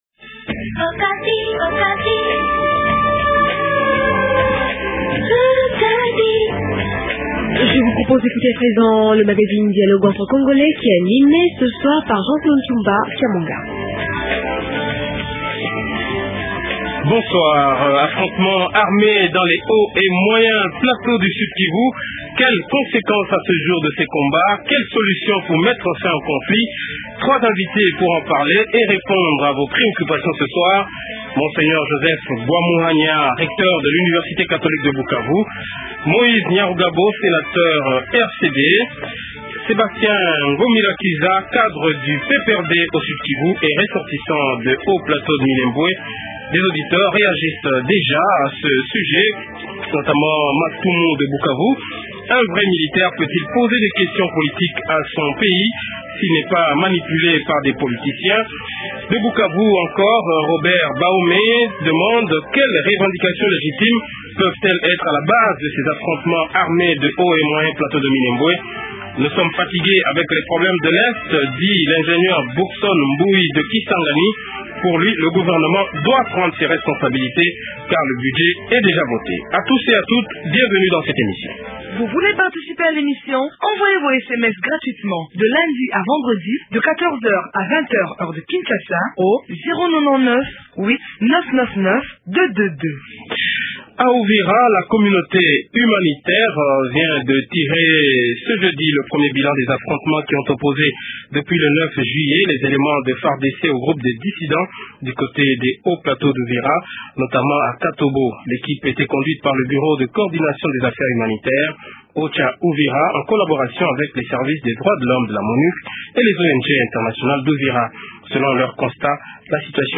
Les opérations se poursuivent mais la 10e région militaire se déclare disposée à recevoir tout insurgé qui voudrait rejoindre le centre de brassage sans condition. rn-Quelles conséquences à ce jour de ces affrontements ? rn-Quelle solution pour mettre fin au conflit ? rnRéponses à ces questions dans Dialogue entre Congolais.